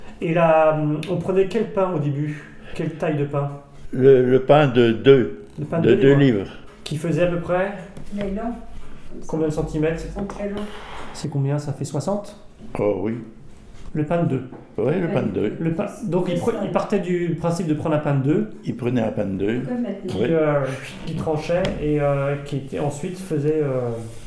Enquête autour du préfou
Catégorie Témoignage